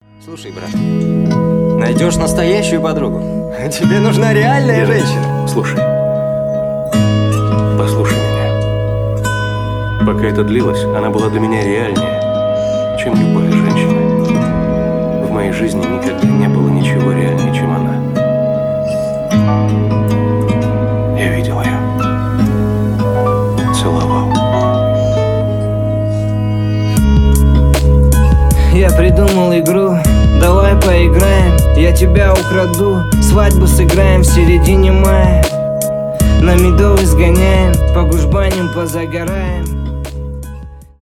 романтические
поп
рэп , хип-хоп